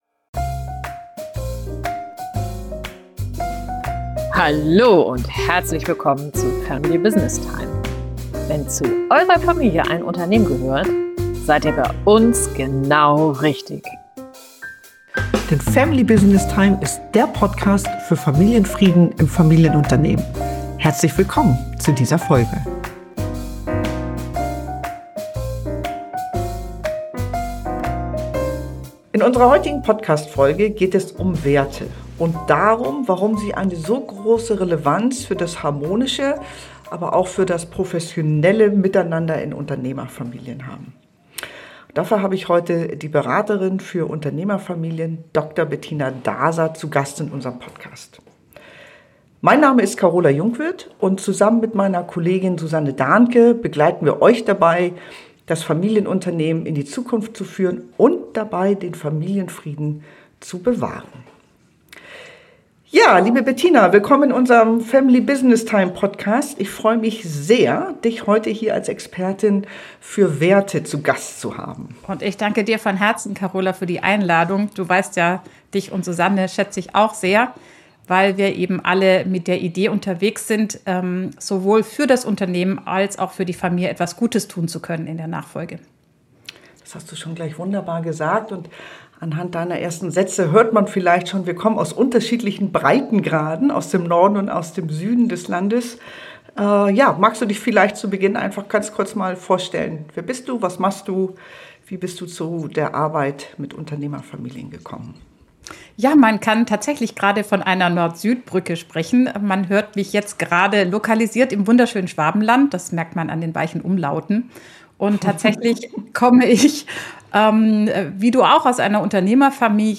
Ein inspirierendes Gespräch